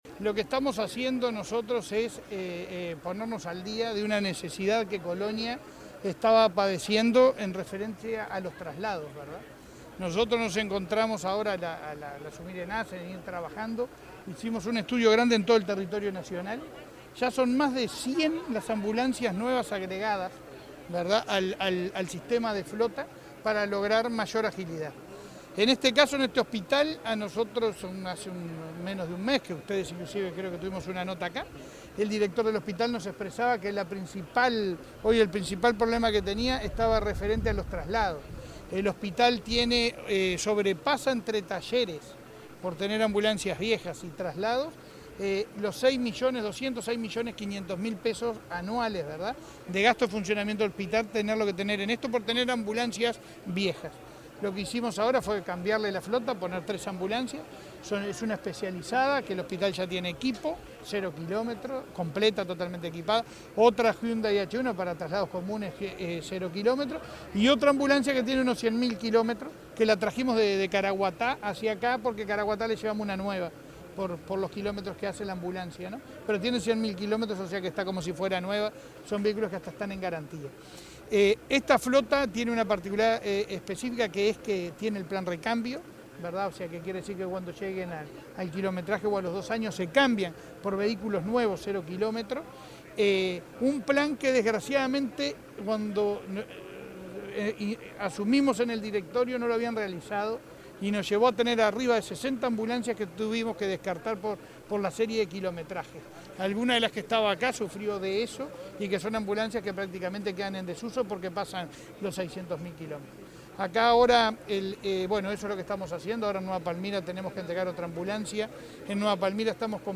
Declaraciones a la prensa del presidente de ASSE
Declaraciones a la prensa del presidente de ASSE 23/03/2022 Compartir Facebook X Copiar enlace WhatsApp LinkedIn El presidente de la Administración de los Servicios de Salud del Estado (ASSE), Leonardo Cipriani, dialogó con la prensa en Carmelo, tras la entrega de varias ambulancias en el departamento de Colonia.